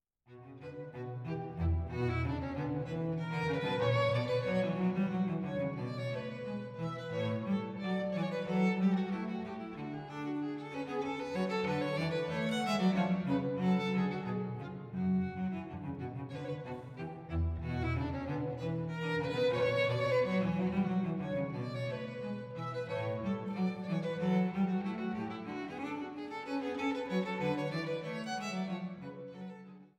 Violine
Violincello